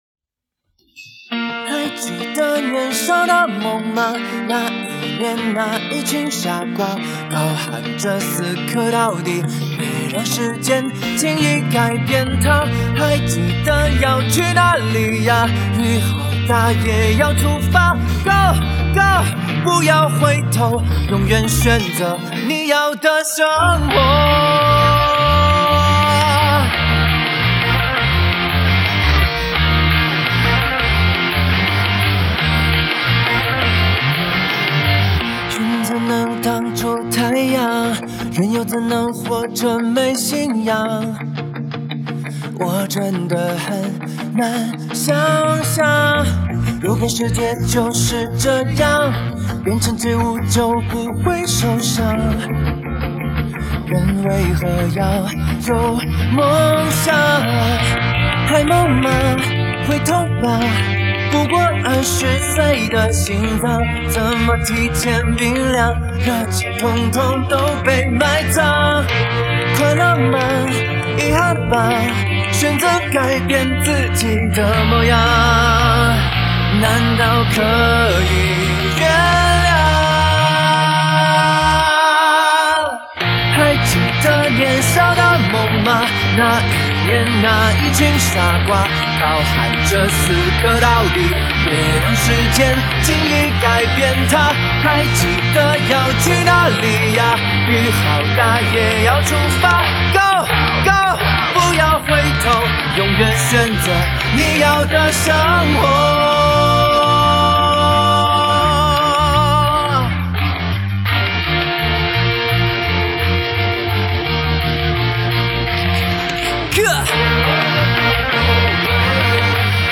151以上 4/4